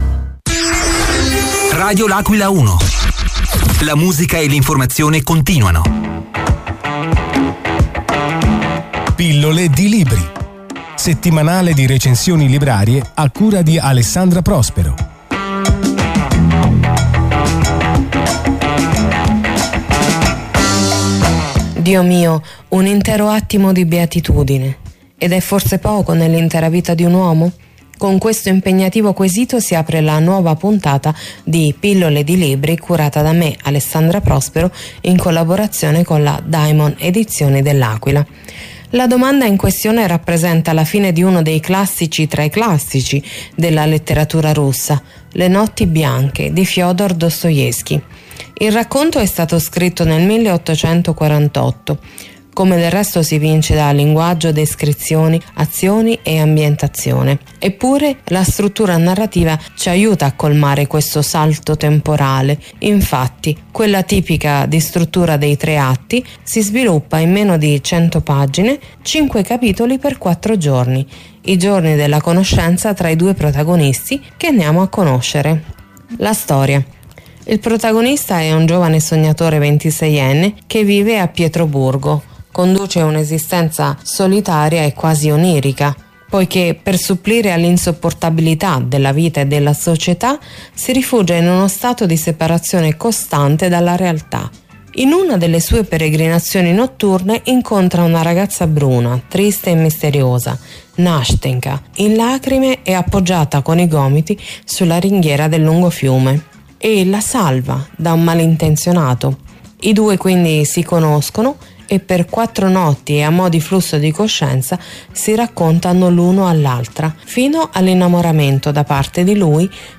L’AQUILA – Complessità psicologica e attimi di beatitudine nella decima puntata della rubrica di recensioni librarie di Radio L’Aquila 1. Un classico tra i classici è l’argomento della puntata di sabato 6 giugno di “Pillole di libri”: la letteratura russa e “Le notti bianche” di Fëdor Dostoevskij approdano alla radio nell’agevole format dell’emittente aquilana in una rinnovata e snella chiave di lettura.